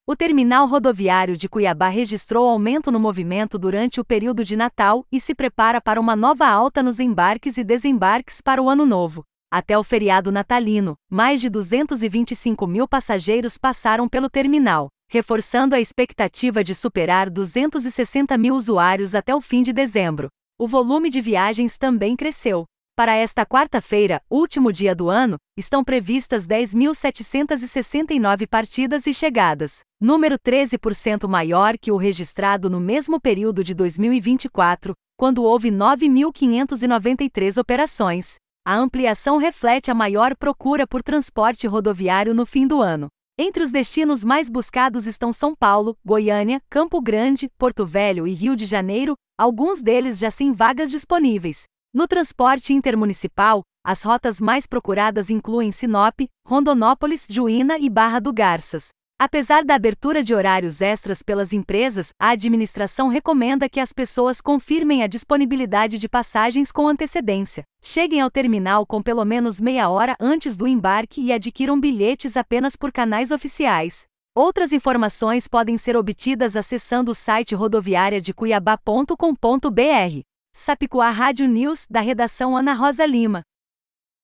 Boletins de MT 31 dez, 2025